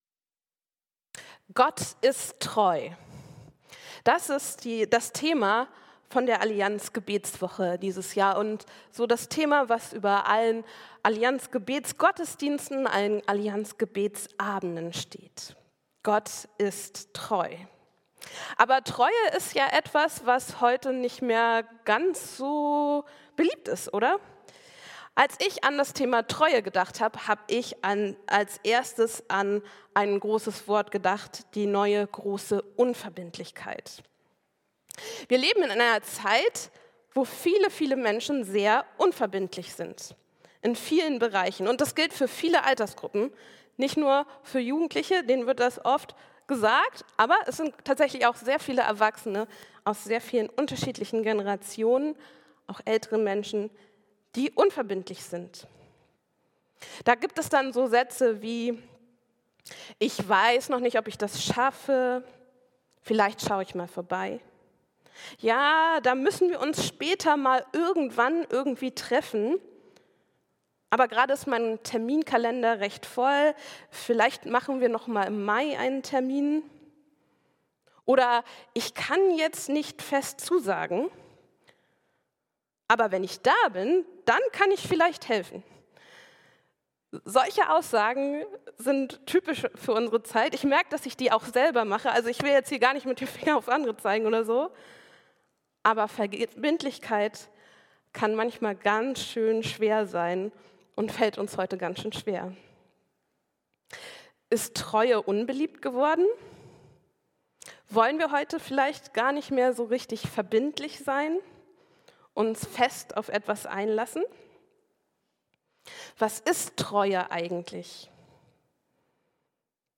Gott ist treu - mitten in einer unverbindlichen Zeit ~ Christuskirche Uetersen Predigt-Podcast Podcast